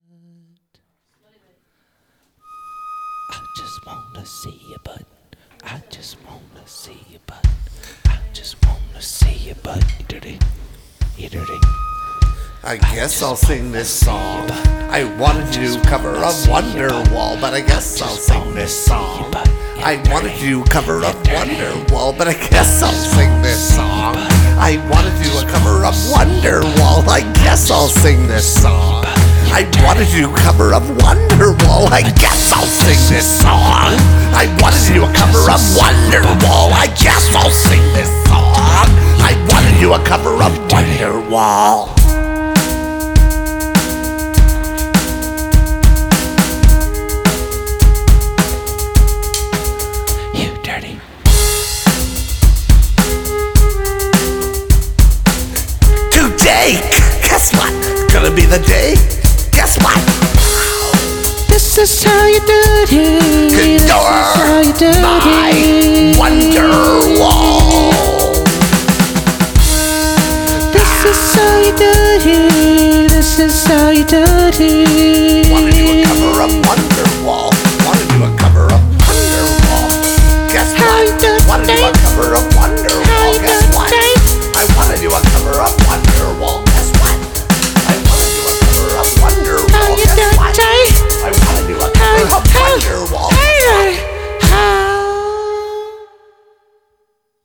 Experimental
Woodwind
Main Vocals
Drums